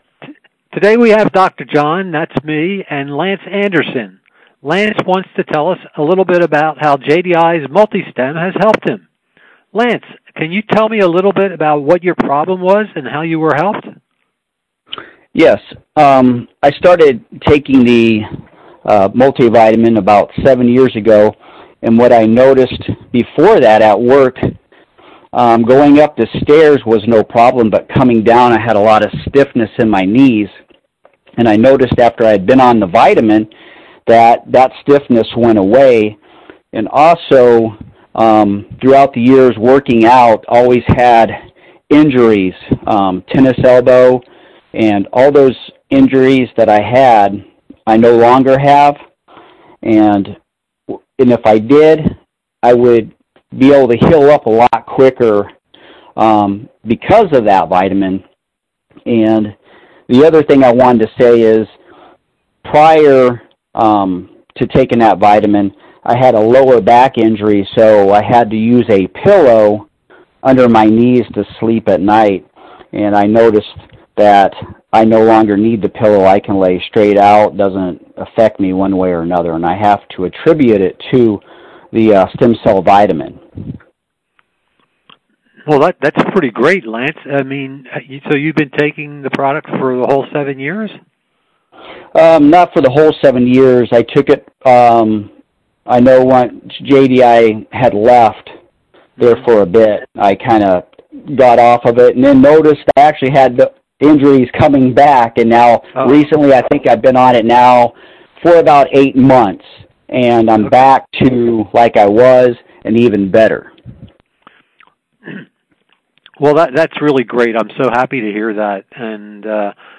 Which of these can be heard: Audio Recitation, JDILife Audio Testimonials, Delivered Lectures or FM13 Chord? JDILife Audio Testimonials